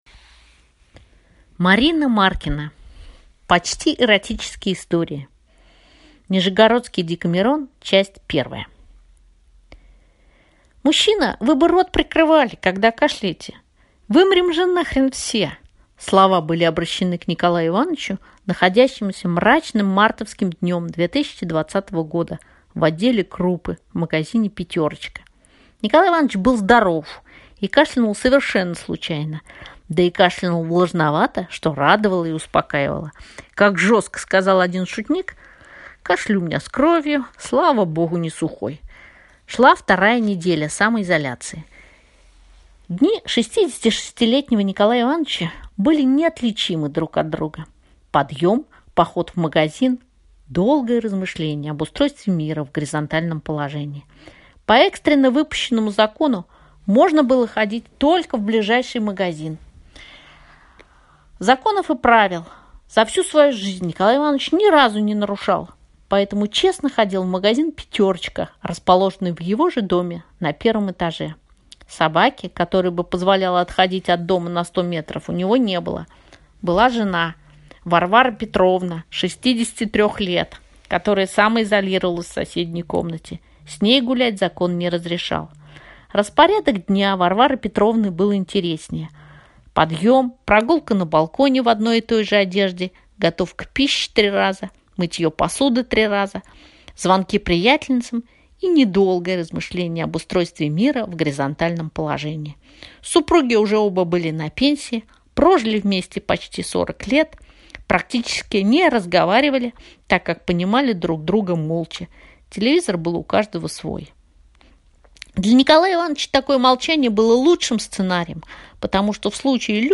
Аудиокнига Почти эротические истории | Библиотека аудиокниг